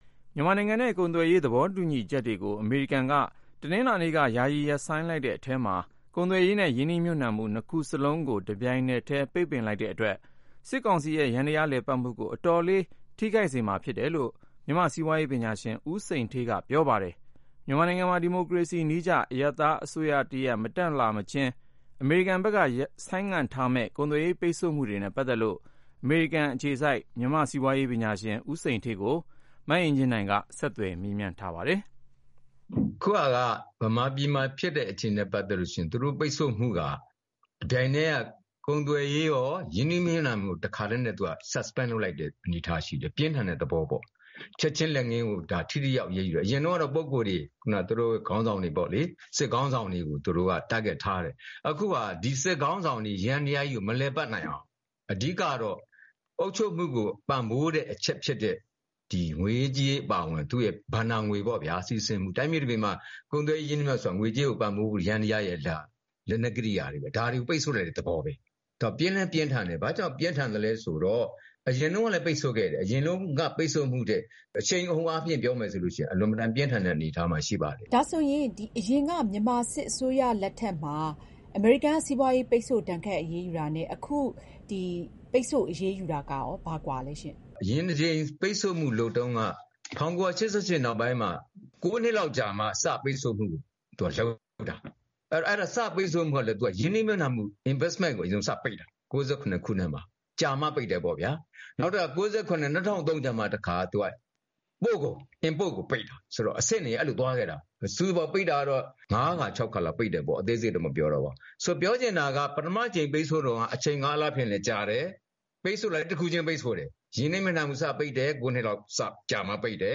အမေရိကန် စီးပွားရေးဒဏ်ခတ်မှု စစ်ကောင်စီအပေါ် ဘယ်လောက်ထိရောက်မလဲ (ဆက်သွယ်မေးမြန်းချက်)